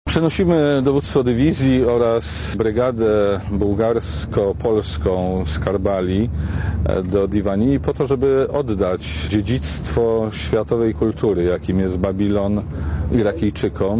Posłuchaj komentarza szefa MON